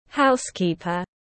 Housekeeper /ˈhaʊskiːpər/